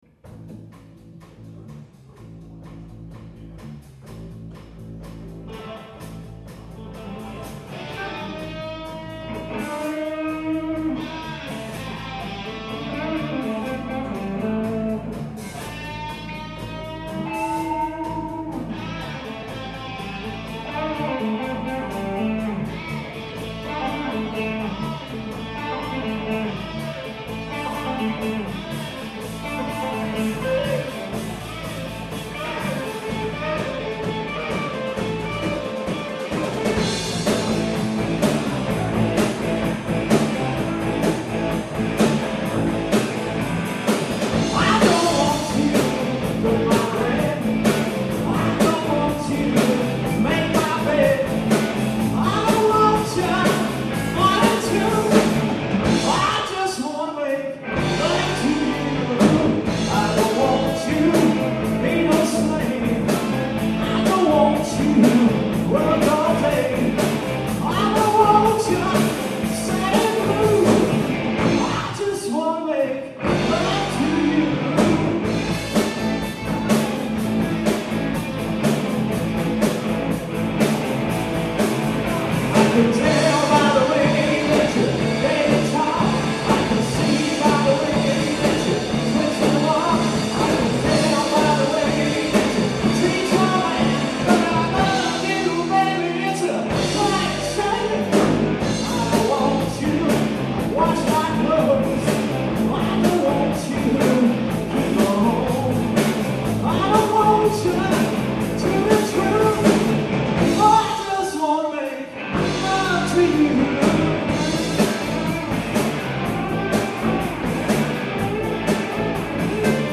DAV Building / Concord, NC
Classic Rock Set: